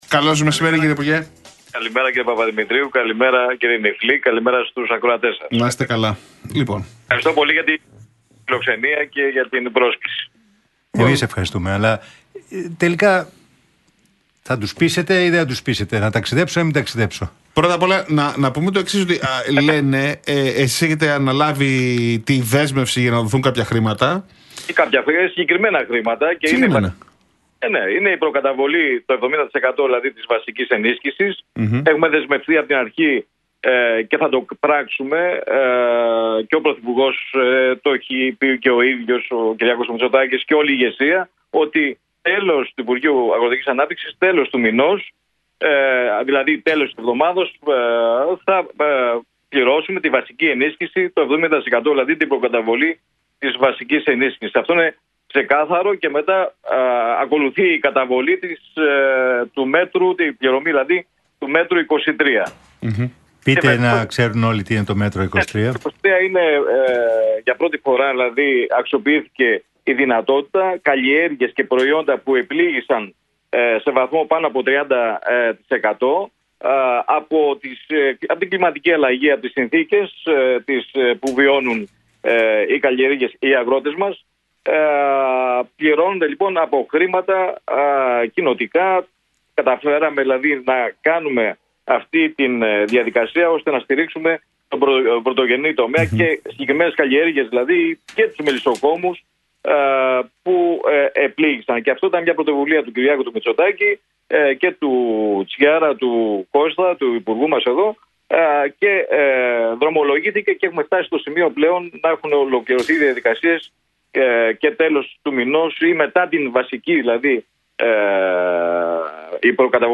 Ανδριανός στον Realfm 97,8: Στο τέλος της εβδομάδας πληρώνεται στους αγρότες το 70% της βασικής ενίσχυσης – Τι έπεται — ΔΕΔΟΜΕΝΟ